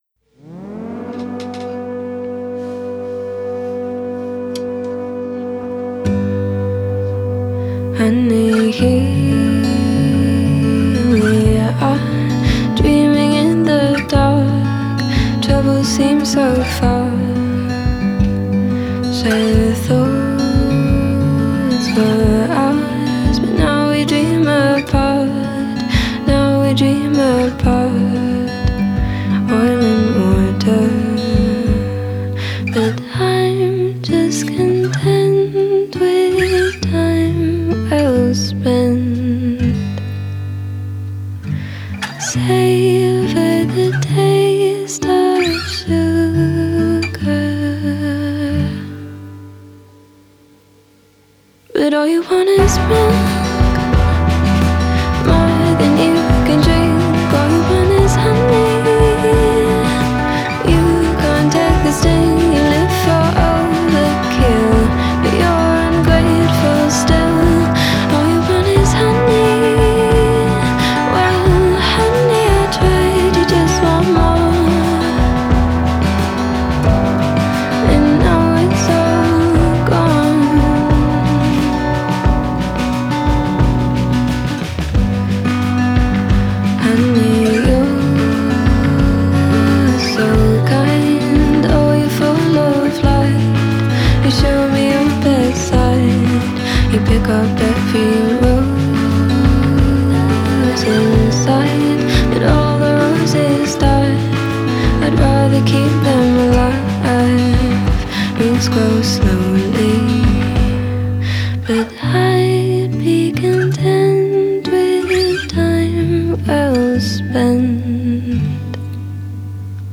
folk and country balladry